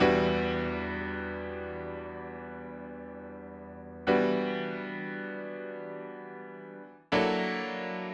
12 Piano PT4.wav